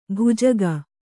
♪ bhujaga